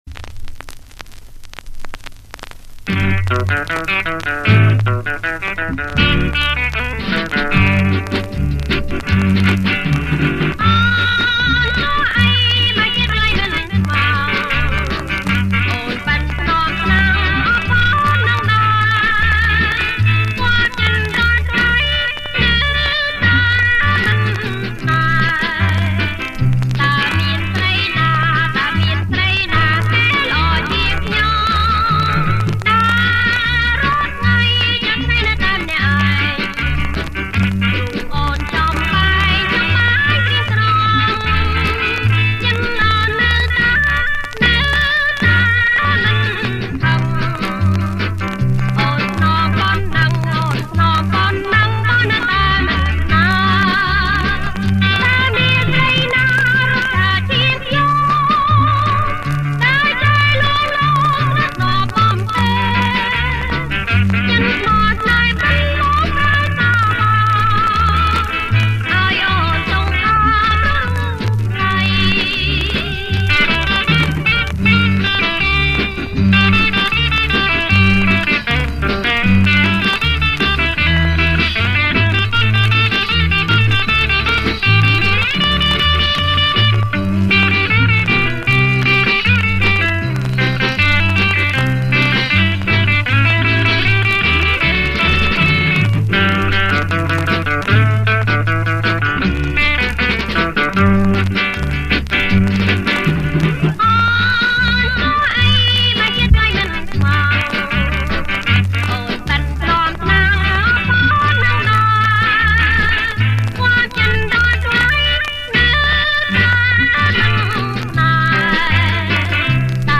• ប្េគំជាចង្វាក់ Twist